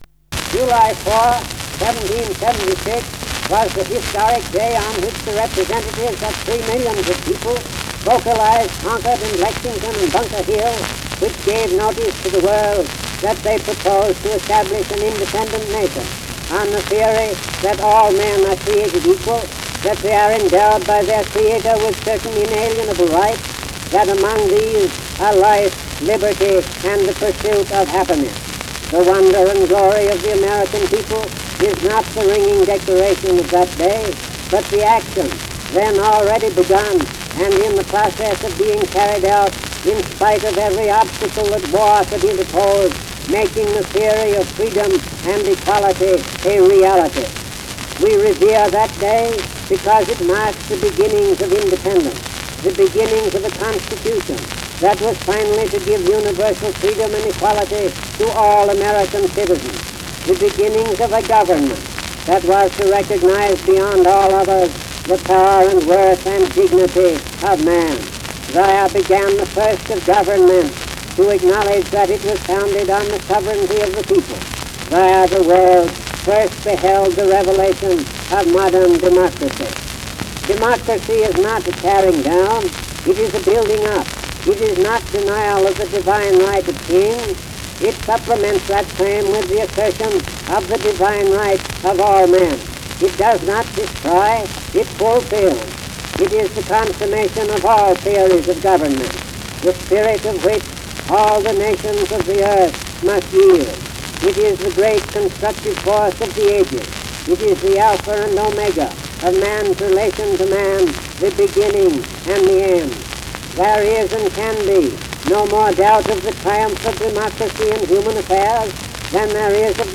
Governor Calvin Coolidge gives a Fourth of July oration on equal rights in Northampton, Massachusetts